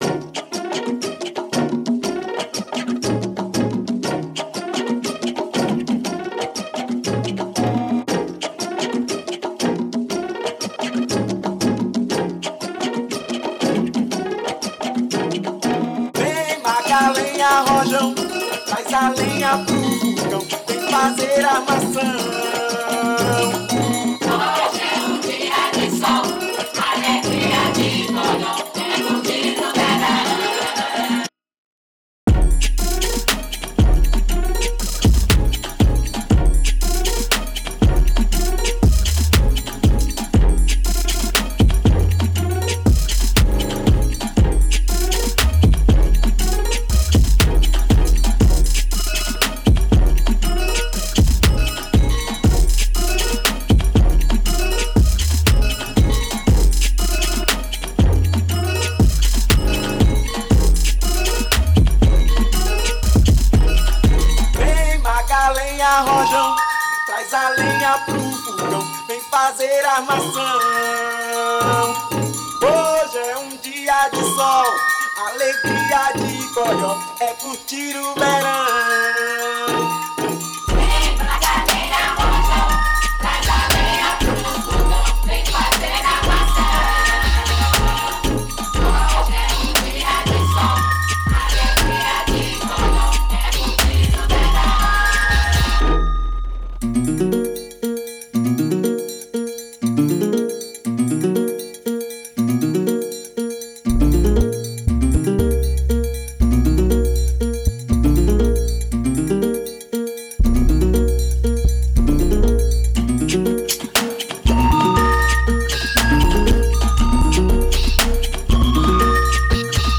bailé inspired remixes